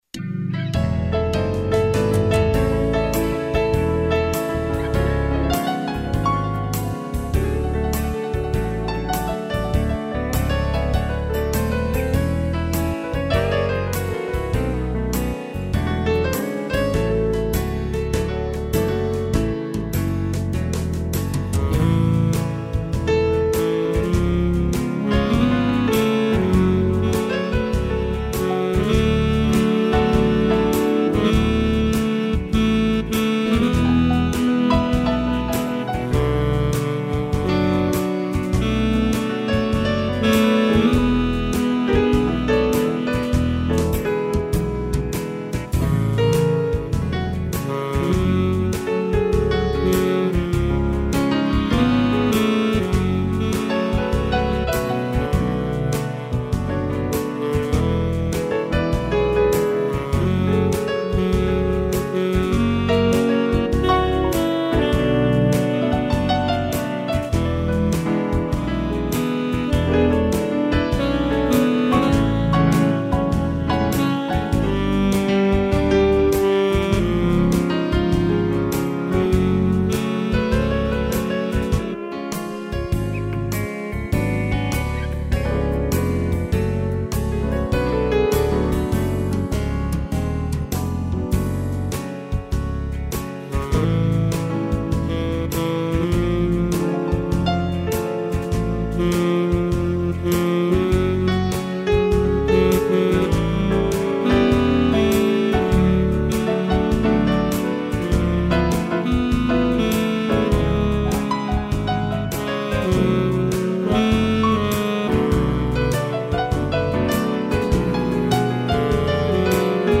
piano
(instrumental)